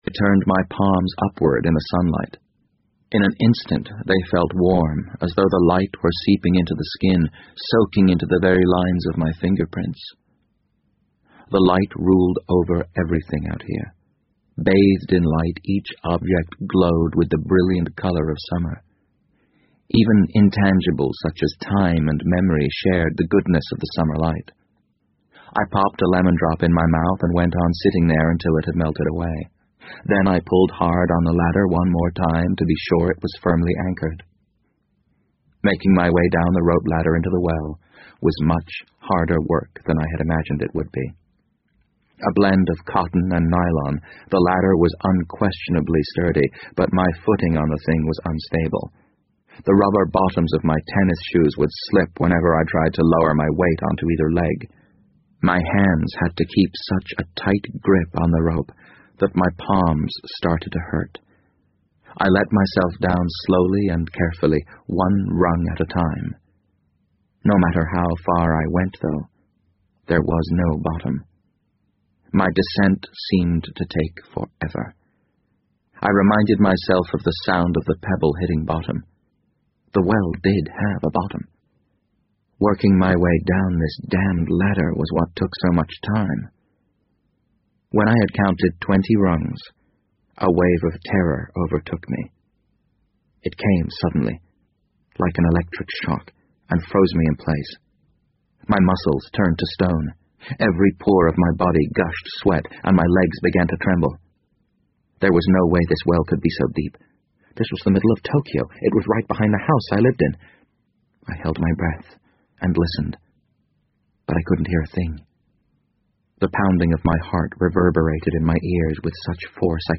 BBC英文广播剧在线听 The Wind Up Bird 006 - 9 听力文件下载—在线英语听力室